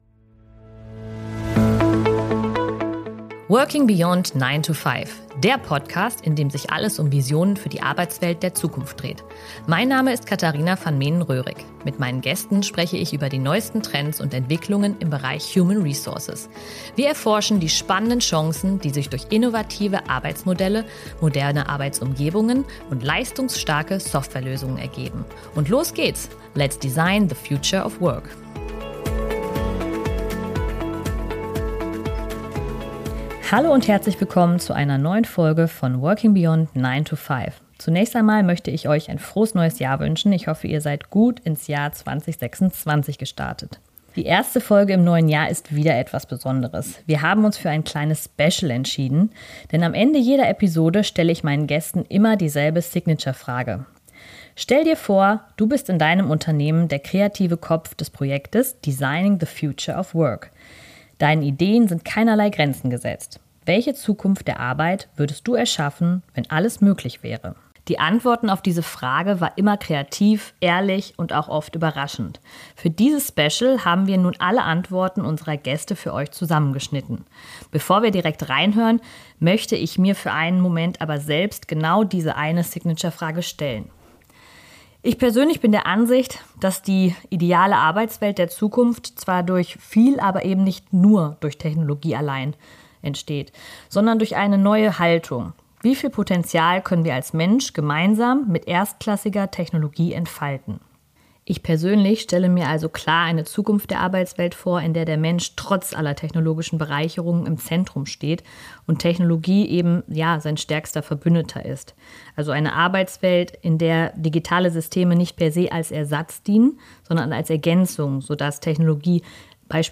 In dieser Special-Folge von 'Working beyond 9 to 5' hören wir die Visionen der bisherigen Gäste zur Zukunft der Arbeit.